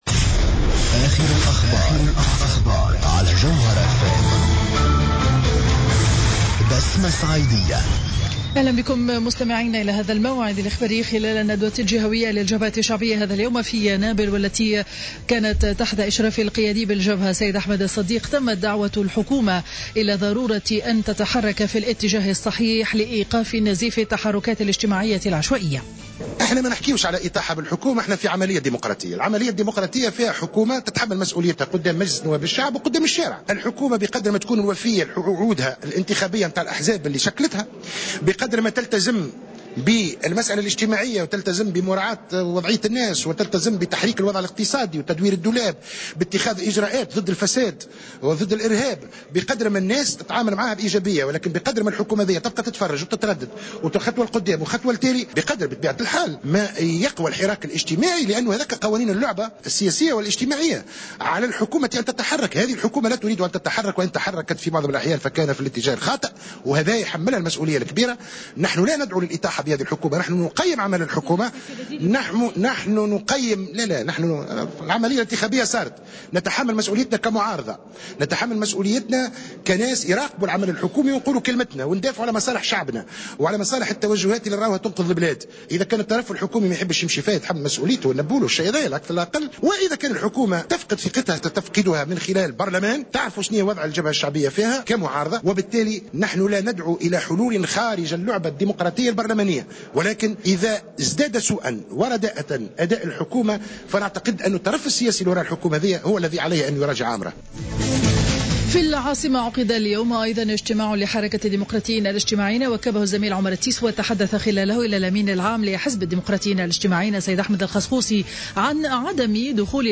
نشرة أخبار منتصف النهار ليوم الأحد 24 ماي 2015